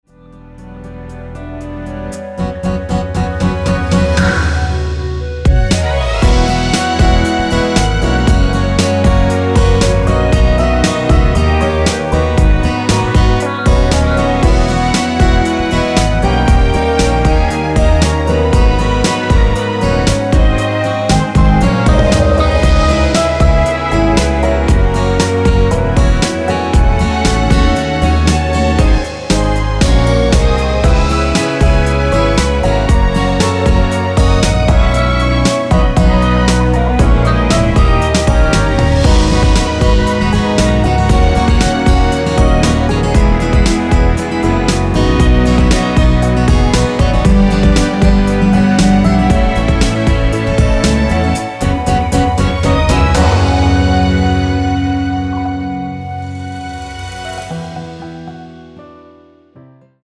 원키에서(-1)내린 (1절+후렴)으로 진행되는 멜로디 포함된 MR입니다.
Abm
앞부분30초, 뒷부분30초씩 편집해서 올려 드리고 있습니다.
중간에 음이 끈어지고 다시 나오는 이유는